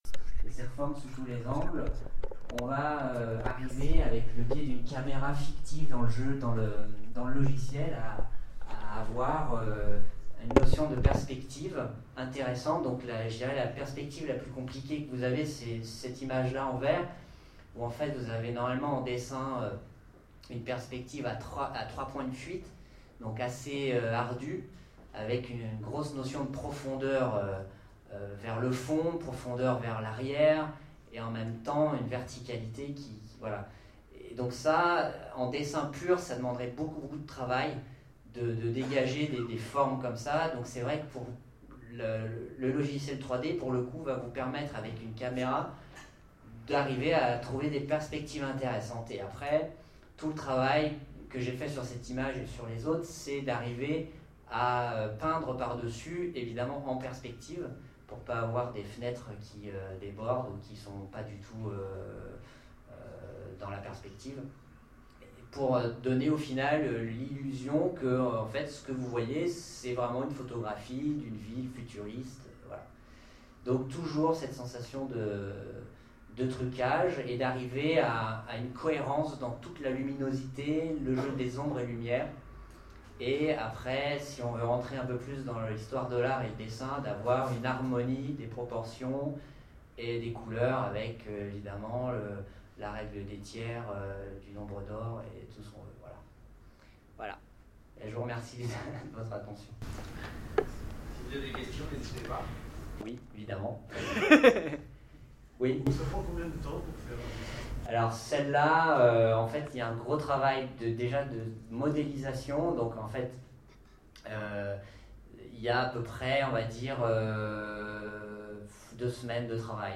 - le 31/10/2017 Partager Commenter Sèvres 2013 : Conférence